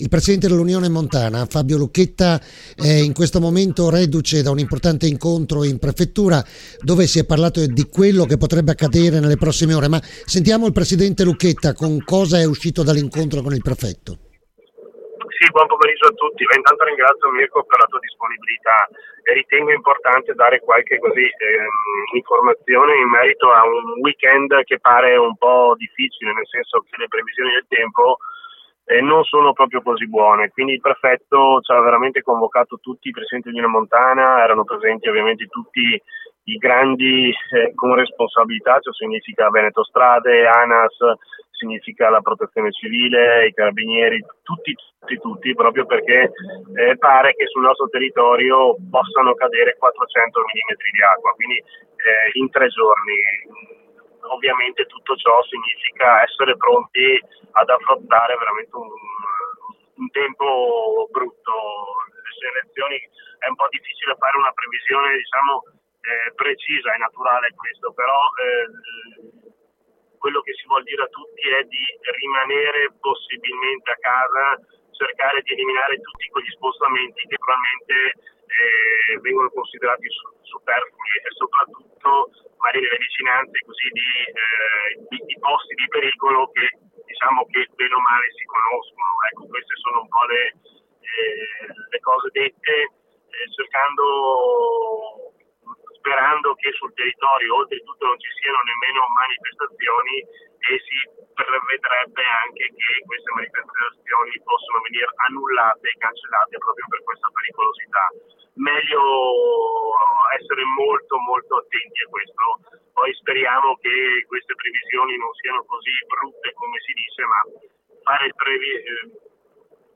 L’INTERVENTO DEL SINDACO SILVIA TORMEN, GIORNALE RADIO DI IERI